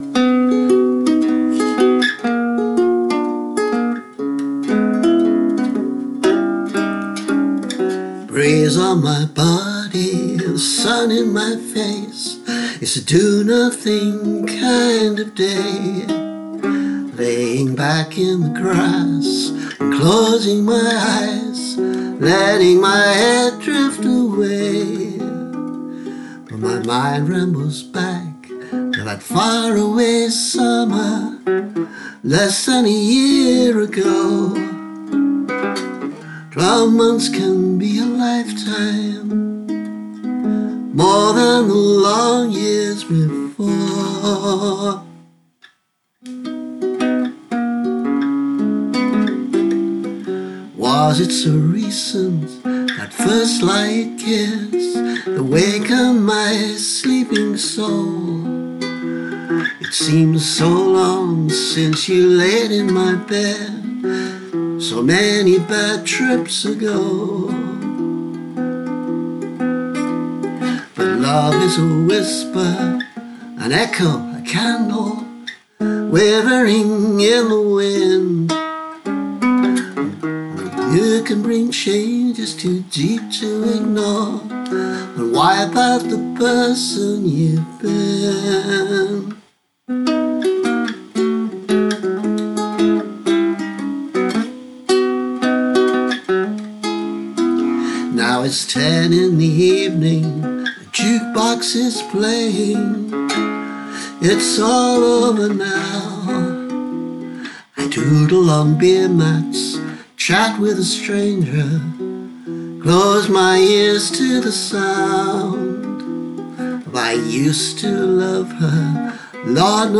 The tune may change a little before I finish with it, and I need to practise a bit more on guilele, which I don’t often play. But I quite like the overall sound, where the light-ish arrangement lifts the rather sad words.